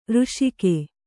♪ řṣike